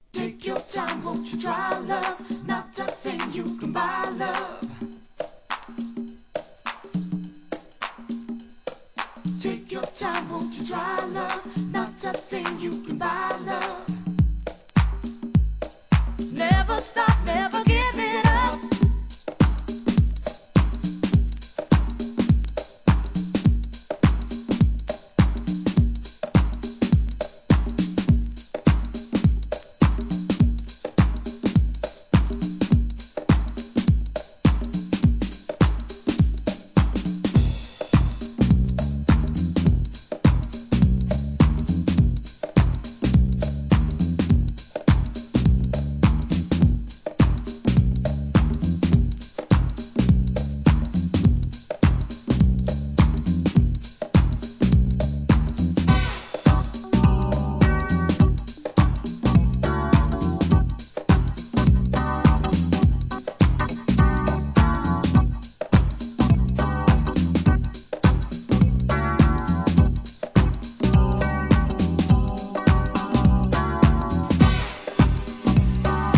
心地よい四つ打ちに素晴らしいヴォーカルが根強い人気!!